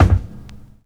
Kick Steveland 1.wav